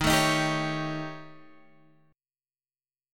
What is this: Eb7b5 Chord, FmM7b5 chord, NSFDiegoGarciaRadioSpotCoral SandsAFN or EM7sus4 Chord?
Eb7b5 Chord